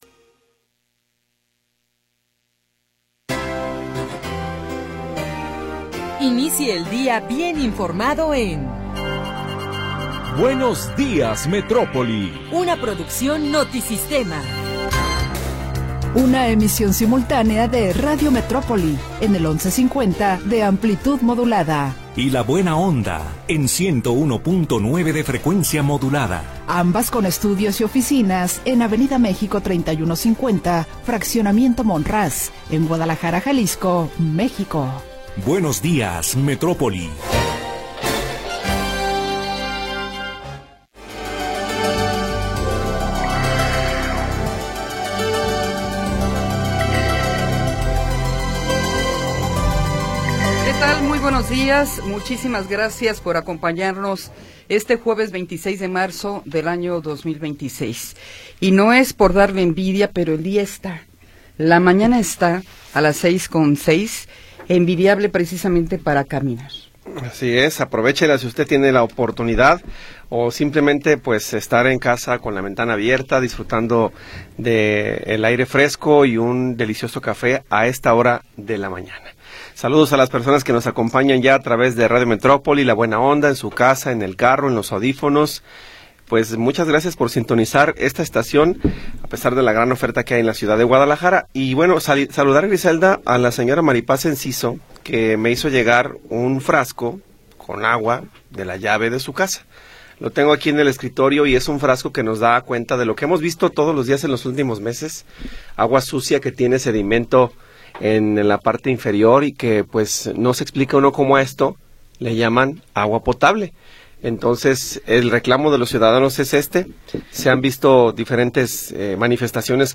Primera hora del programa transmitido el 26 de Marzo de 2026.